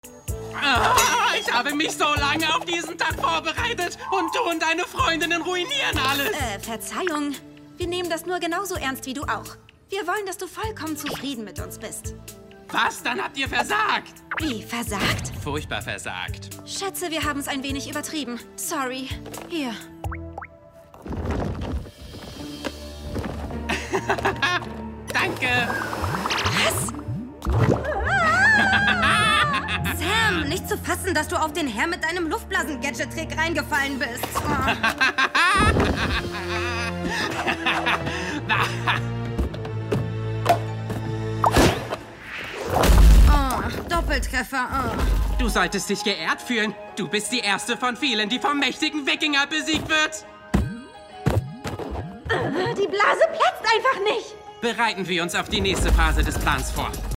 sehr variabel
Mittel minus (25-45)